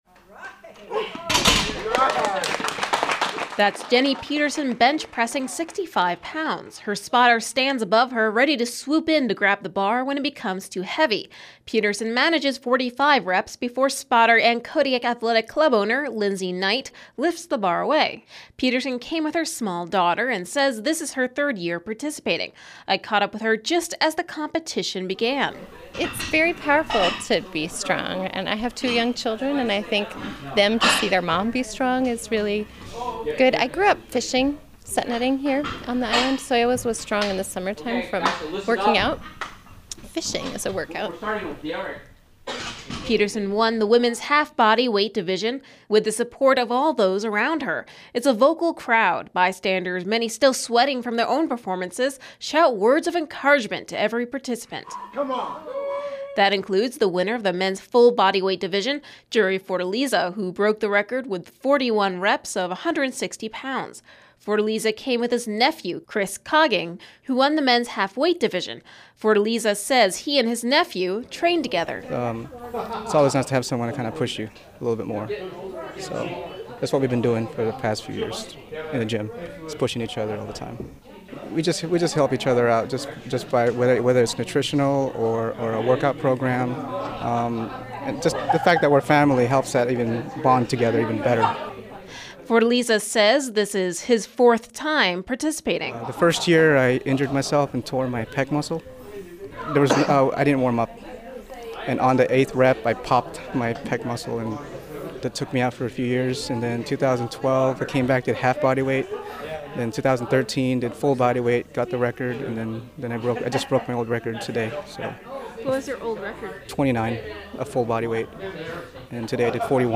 It’s a vocal crowd. Bystanders, many still sweating from their own performances, shout words of encouragement to every participant.